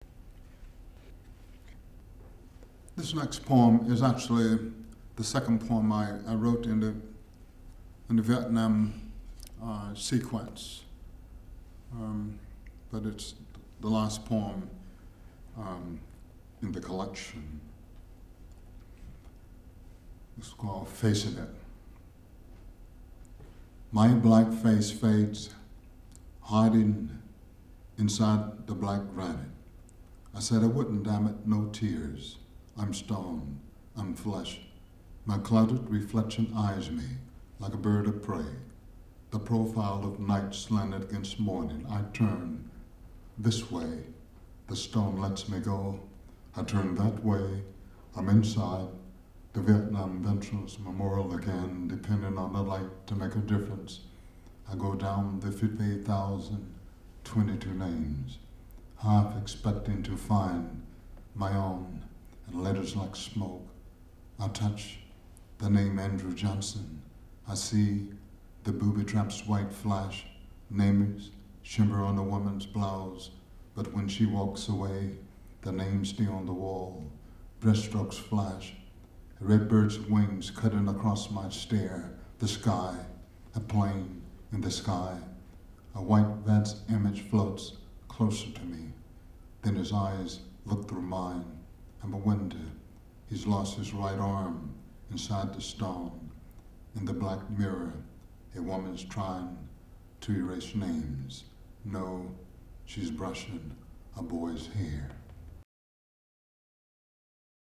Yusef Komunyakaa reading at University of Glasgow (12th September 2009).